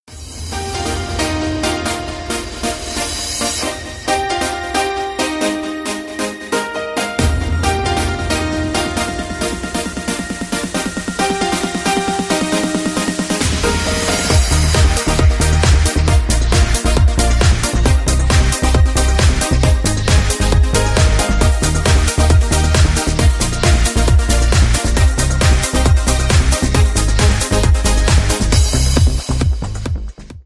mitreißender und mitsingbarer
• Sachgebiet: Pop